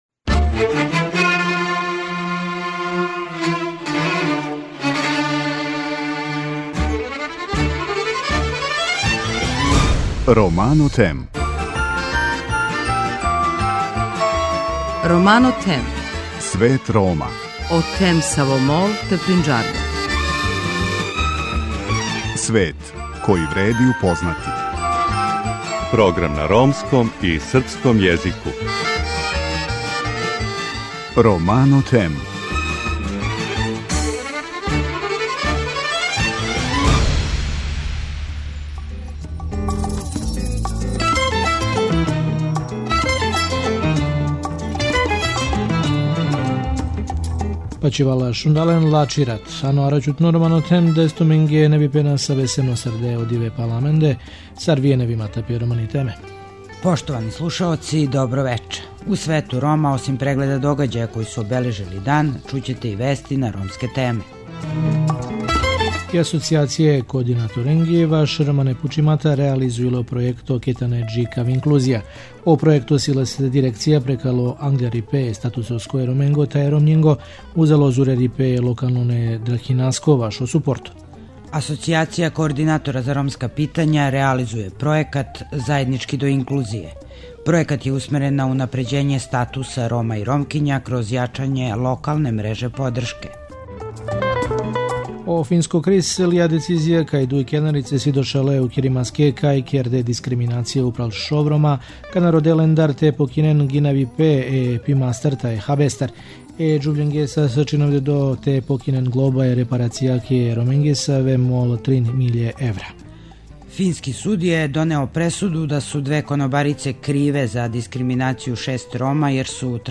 Чућемо и други део интервјуа
преузми : 19.06 MB Romano Them Autor: Ромска редакција Емисија свакодневно доноси најважније вести из земље и света на ромском и српском језику.